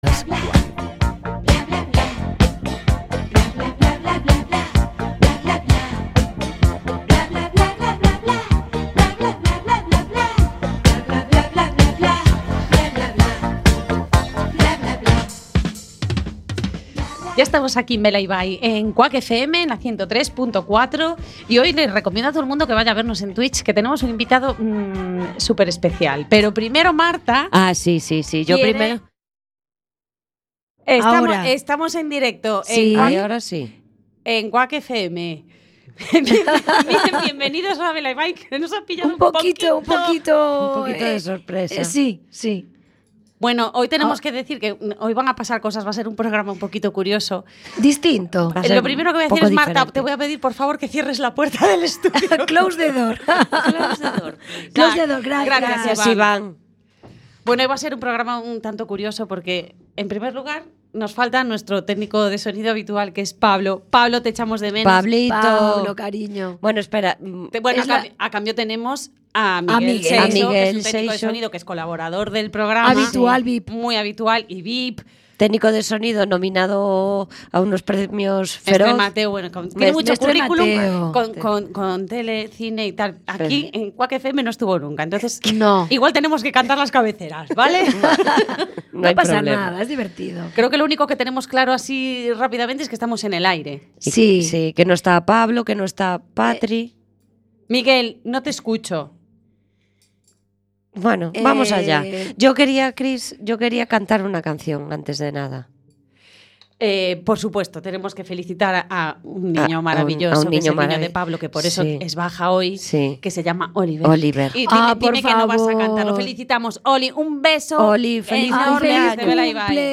Entre risas y sin demasiados filtros, salen a la luz secretos, manías y alguna que otra historia que quizá nunca pensaron contar en antena. Un capítulo muy de casa, muy de amigas, en el que las conversaciones van donde quieren ir… incluso hasta un momento tan inesperado como divertidísimo hablando de sofocos repentinos y de esas etapas misteriosas que llegan sin avisar.